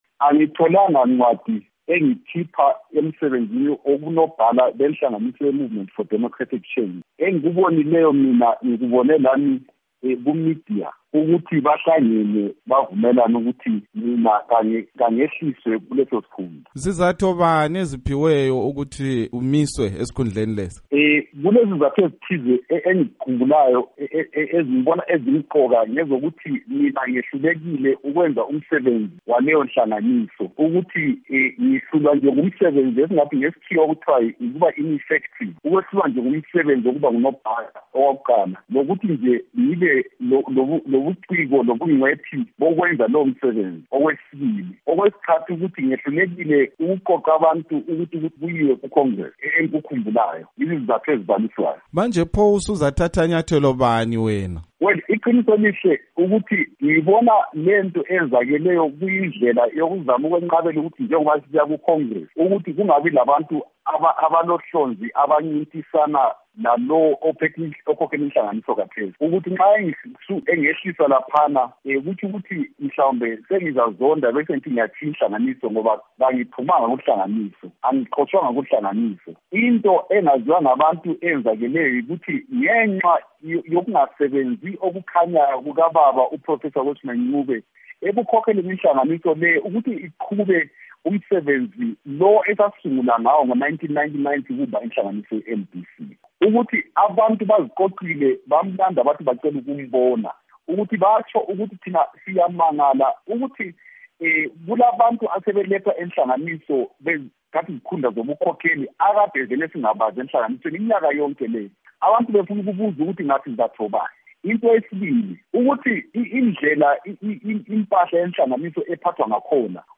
Ingxoxo loMnu. Moses Mzila Ndlovu